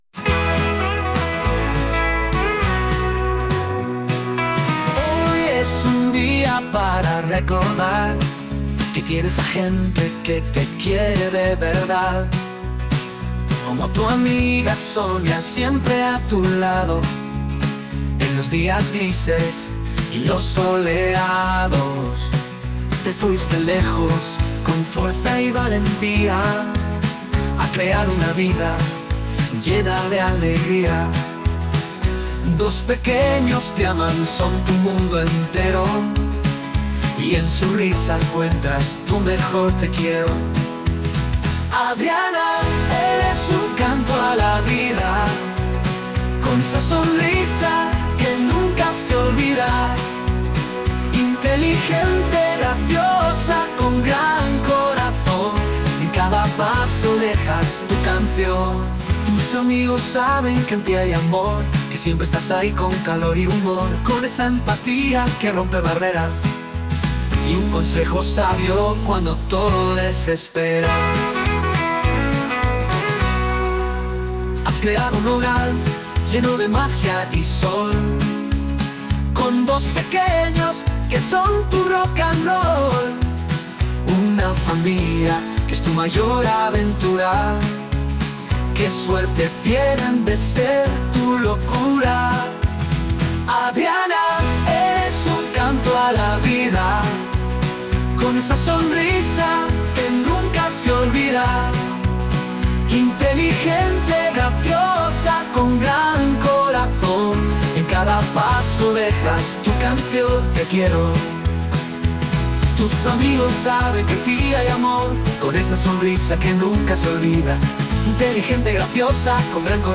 Cancion con inteligencia artificial